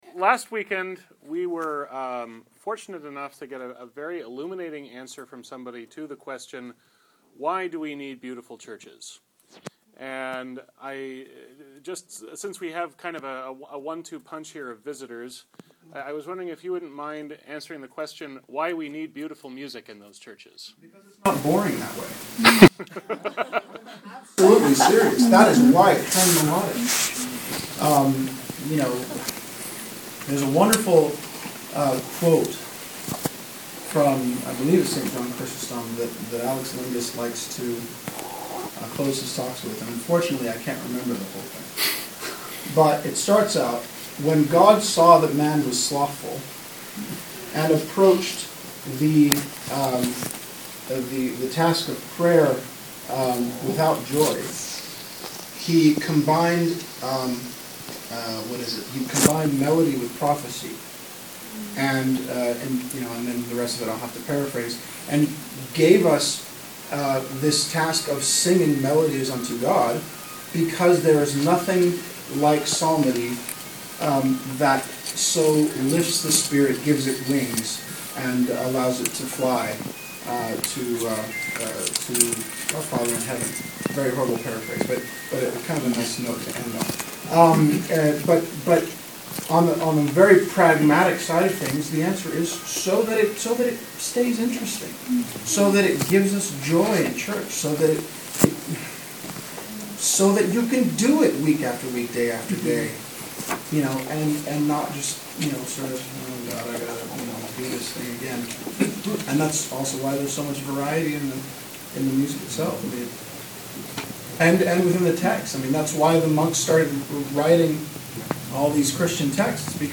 (I will note that, thanks to how the acoustics at All Saints work, or rather don’t work, I had to be a bit creative in figuring out how to edit this so that it could be heard. There are still a couple of spots that are wonkier than I’d like, but I think it’s all audible.